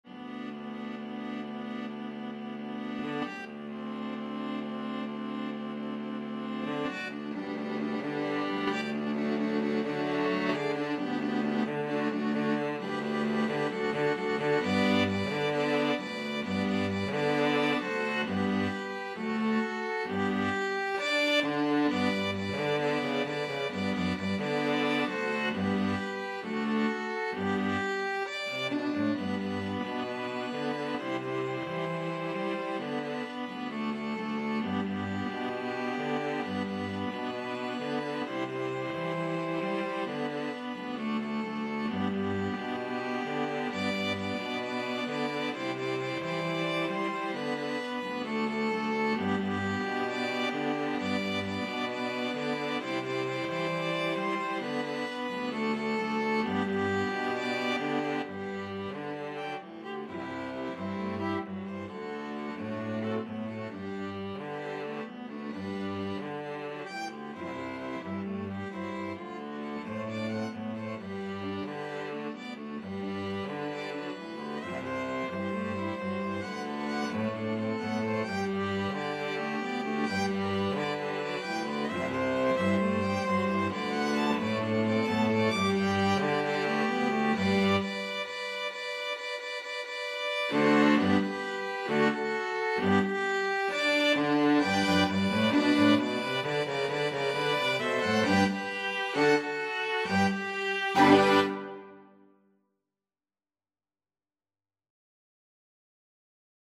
Violin 1Violin 2ViolaCello
Molto allegro =c.132
4/4 (View more 4/4 Music)
world (View more world String Quartet Music)